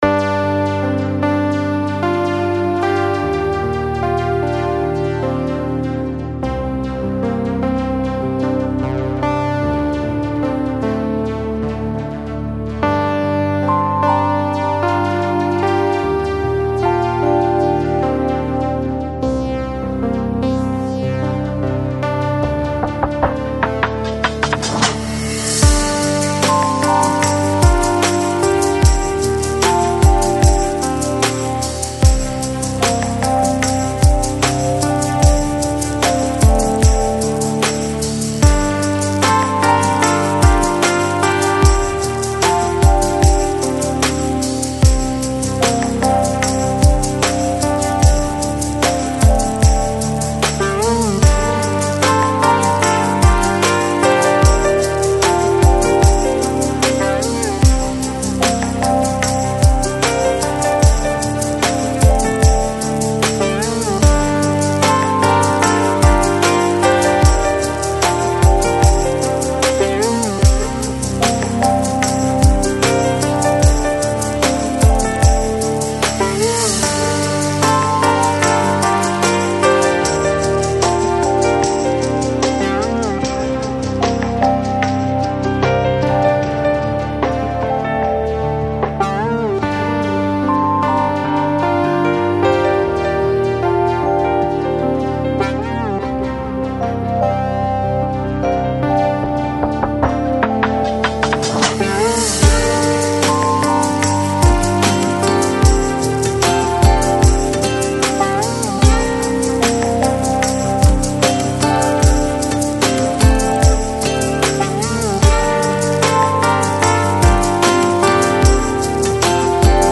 FLAC Жанр: Balearic, Downtempo Издание